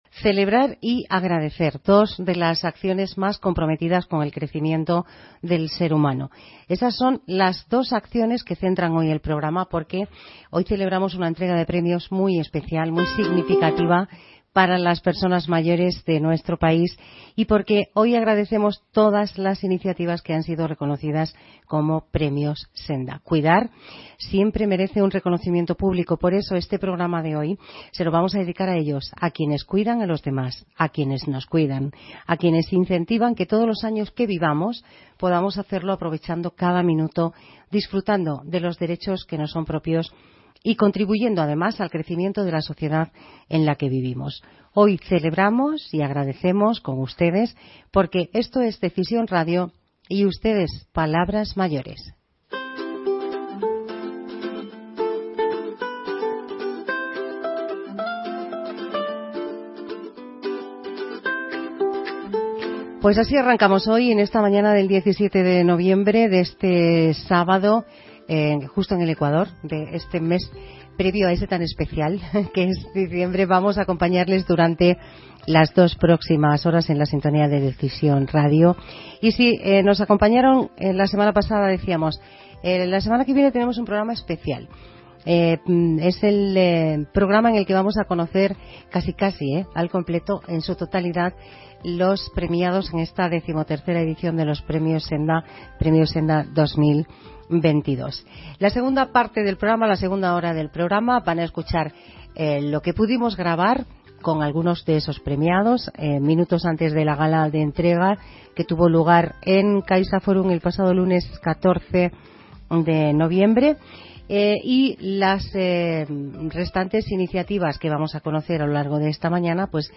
Programa especial Premios Senda 2022, grabado en parte antes de la gala de entrega de los premios, con sus protagonistas
En esta ocasión, parte del programa se grabó minutos antes de la Gala de entrega de los Premios Senda, gracias a los compañeros de Decisión Radio.